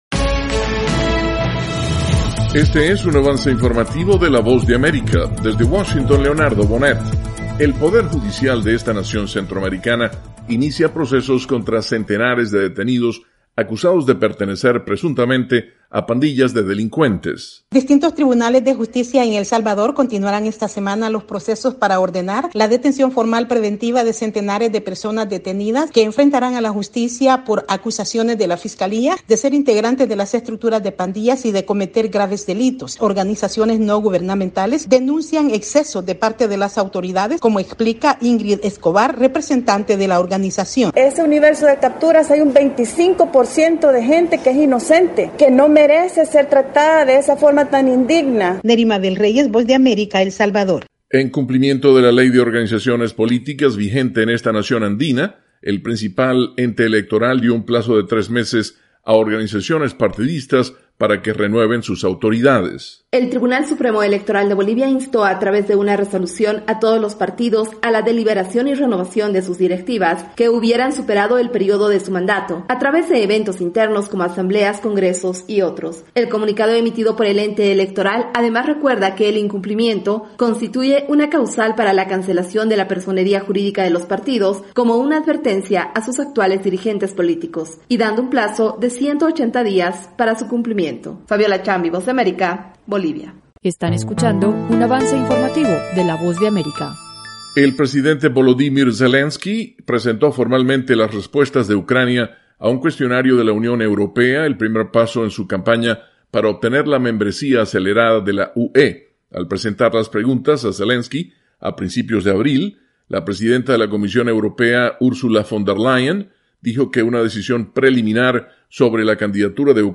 Avance Informativo - 3:00 PM